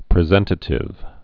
(prĭ-zĕntə-tĭv)